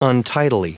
Prononciation du mot : untidily